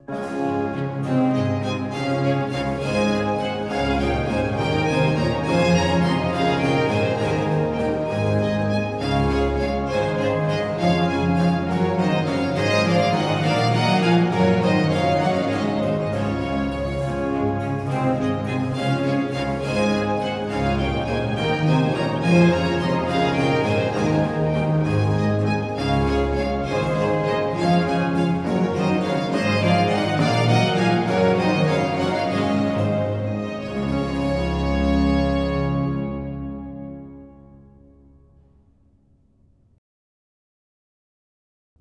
05.Trio 'O di notte felice' (concl. strum.).mp3 — Laurea Triennale in Scienze e tecnologie della comunicazione